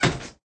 thud_1.ogg